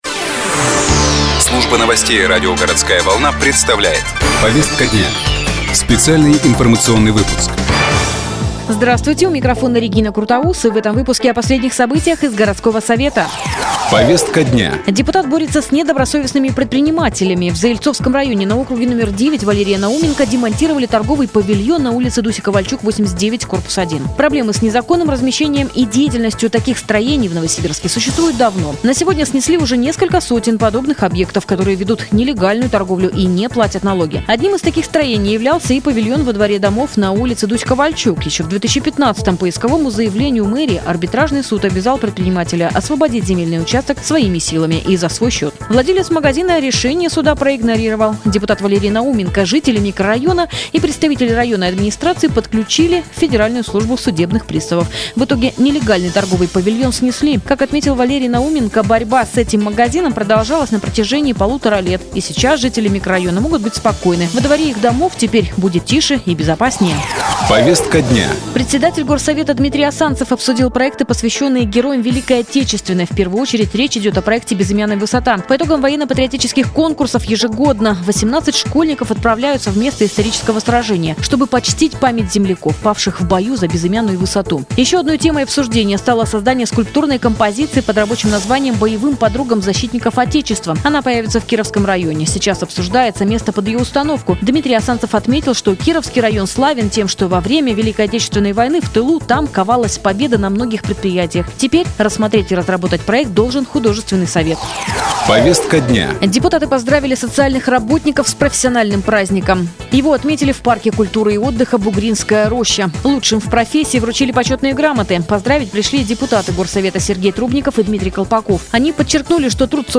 Запись программы "Повестка дня", транслированная радио "Городская волна" 17.06.2016